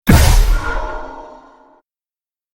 Free SFX sound effect: Magic Poof.
Magic Poof
yt_BZqqxSVItHk_magic_poof.mp3